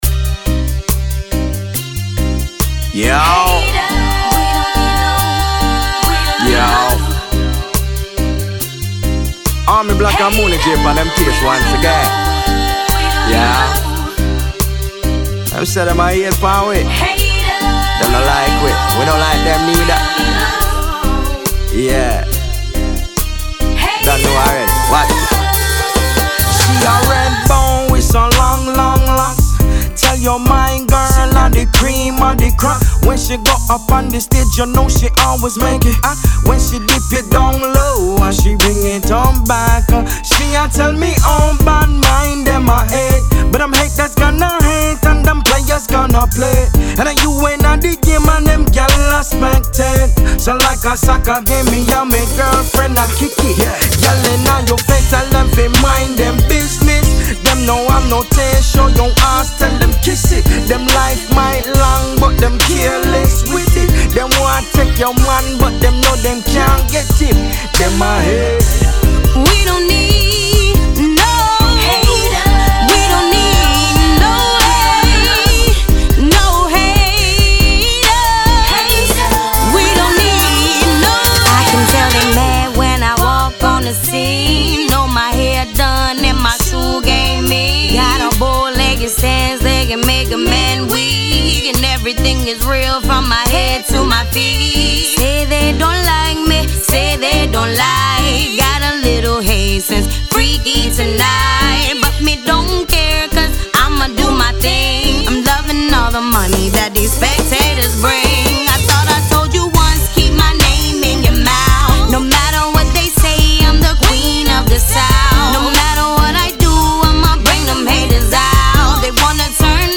RnB
Description : Some funky r&b / trap / reggae from my homeg